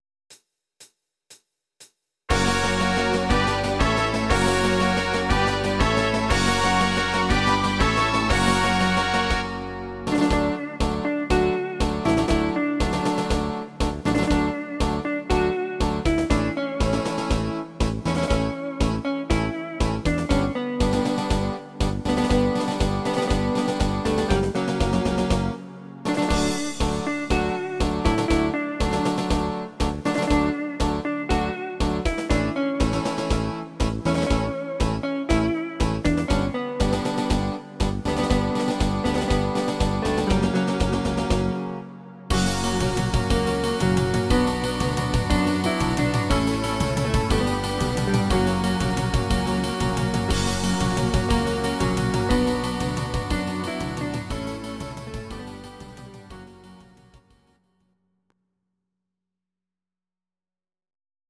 These are MP3 versions of our MIDI file catalogue.
Please note: no vocals and no karaoke included.
instr. Gitarre